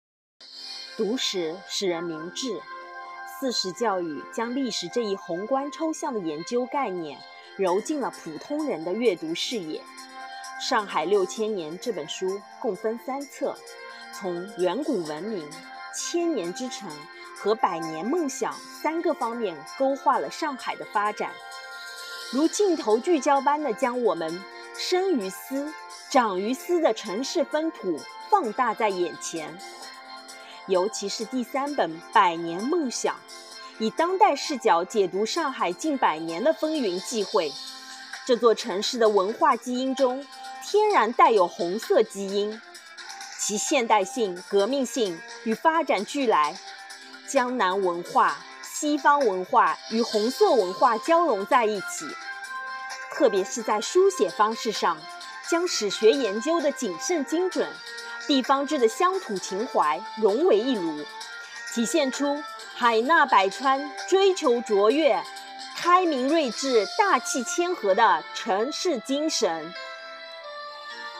诵读音频：点击收听